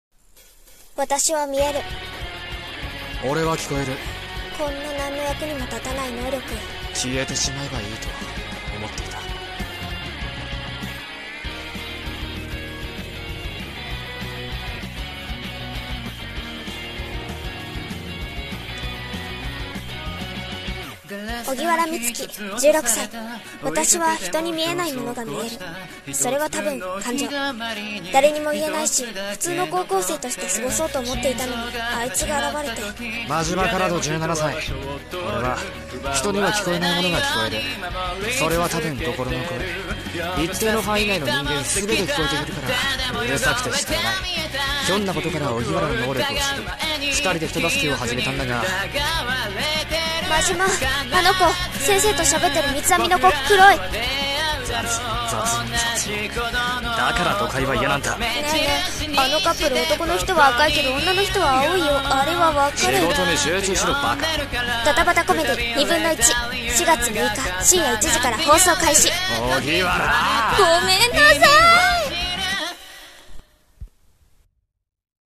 【アニメ告知CM風声劇台本】ニブンノイチ。【２人声劇】